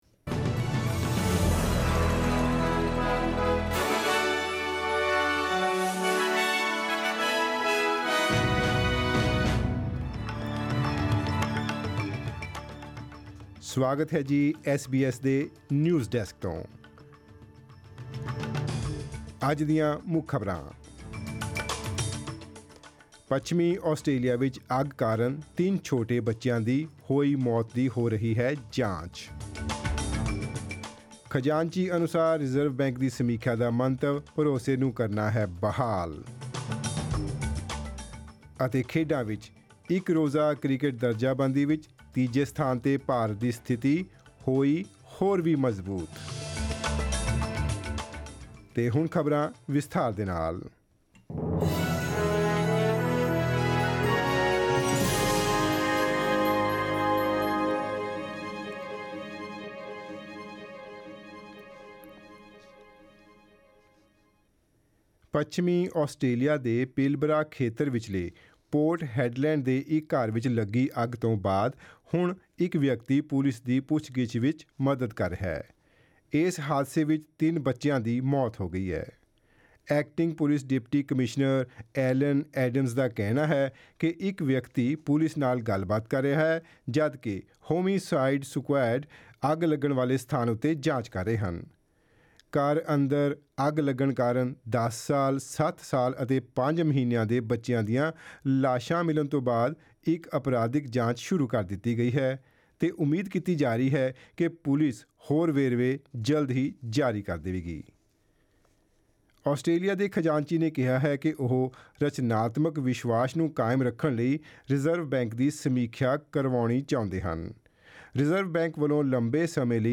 Click on the audio button to listen to the news bulletin in Punjabi.